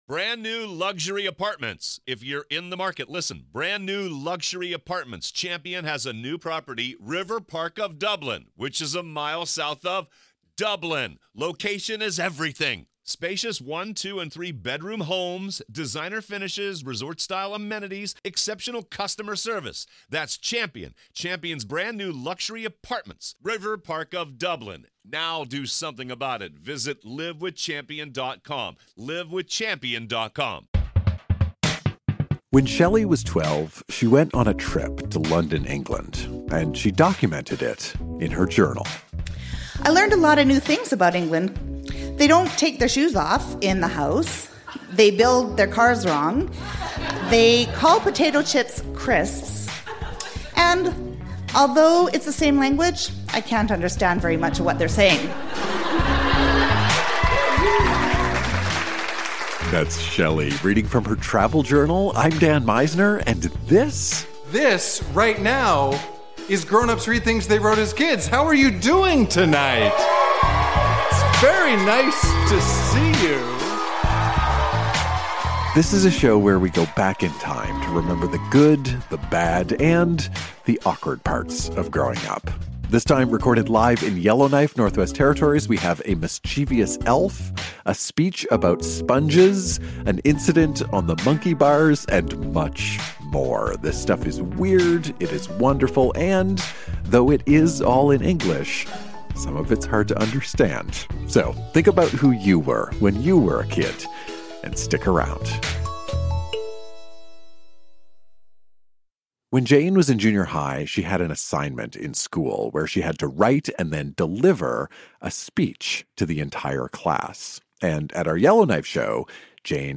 Recorded live at the Black Knight in Yellowknife.